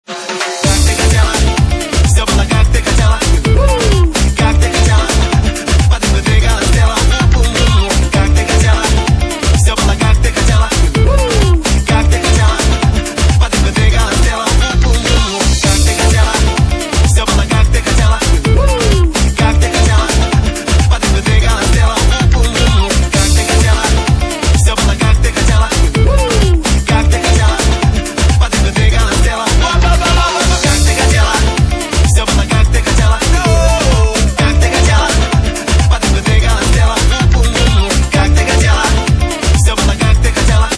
Электронная
Клубный микс